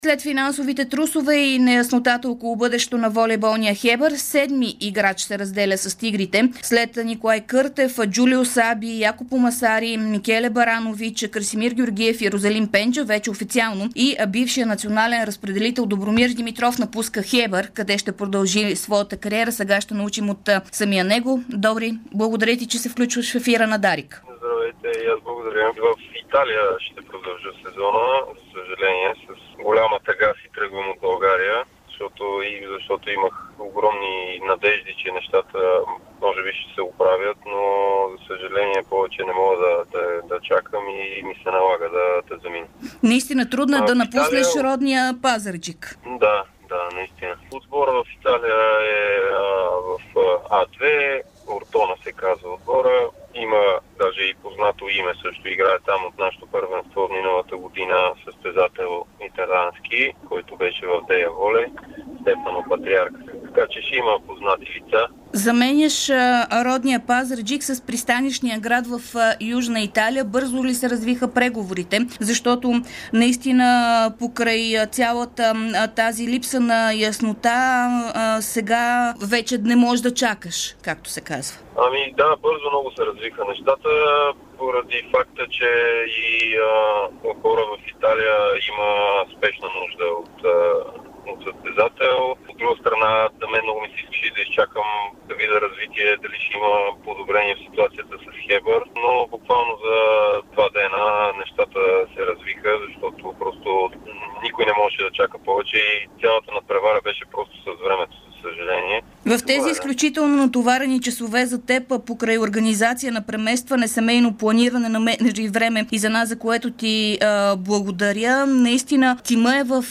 ексклузивно интервю